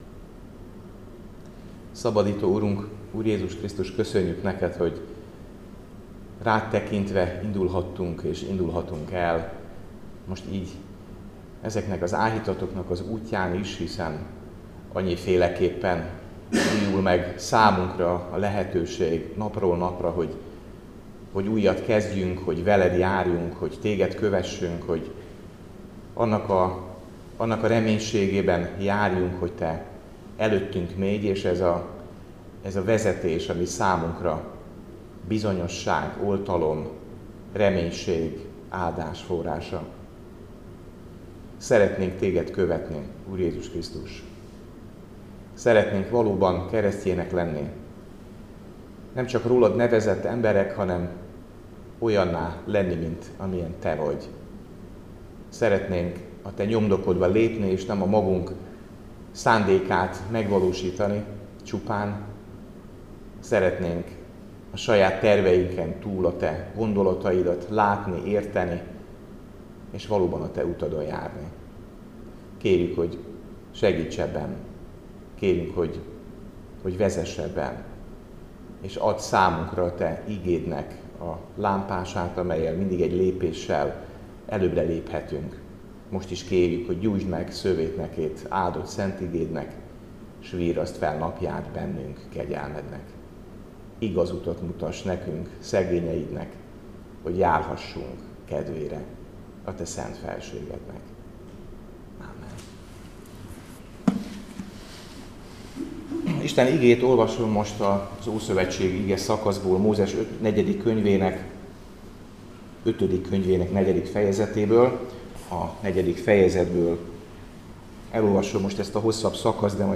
Áhítat, 2025. január 7.